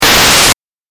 レトロゲーム （105件）
8bitダメージ6.mp3